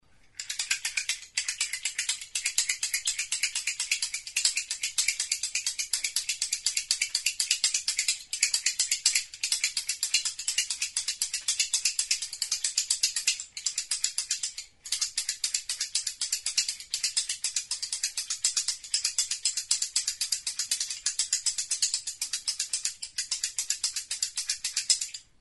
Music instrumentsEZKILATXOA; TIKITIKI; Hots jostailua
Idiophones -> Struck -> Indirectly
Recorded with this music instrument.
Gerrian ardatz txiki bat du eta bi hatzekin burutik heldurik astintzerakoan besoek dituzten bi bolatxoek gona-kanpana jotzerakoan erritmikoki 'tikitiki' hotsa ematen dute.